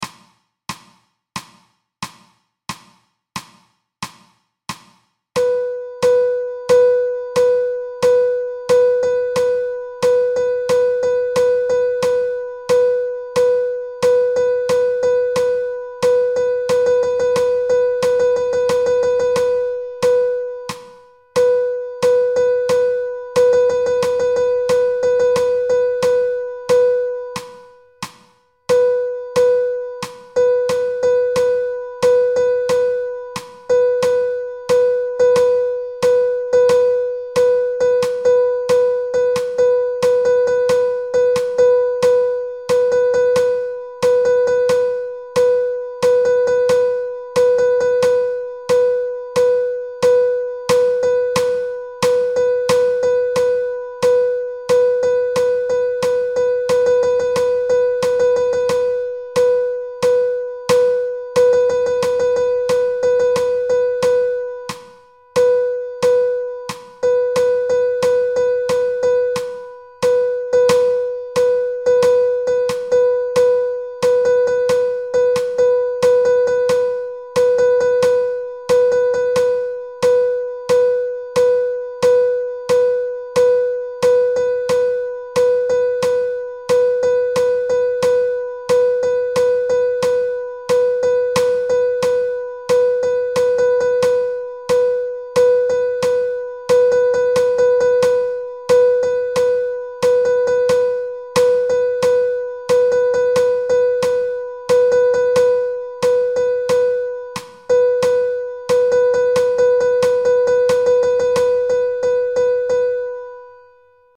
Sight Reading 1 (Sr01) - Rhythms in 4/4, 3/4 and 5/4.